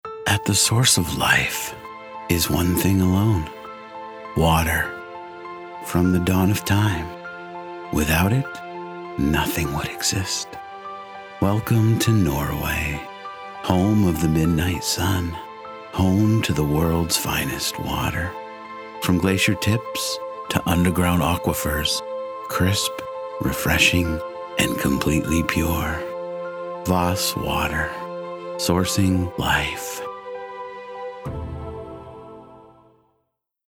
A Professional American Male Voice Actor With A Smooth & Vibrant Delivery
Smooth Male Voice For Voss Water